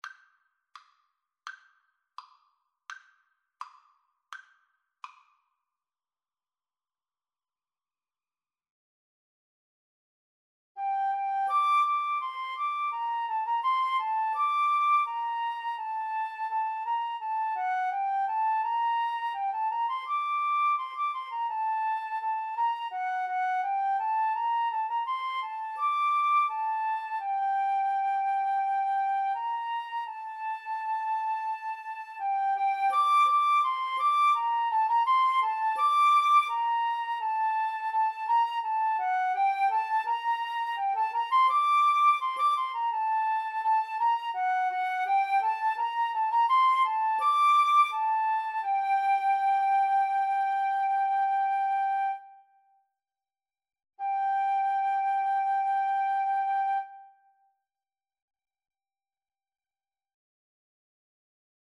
G minor (Sounding Pitch) (View more G minor Music for Flute Duet )
Steady two in a bar ( = c. 84)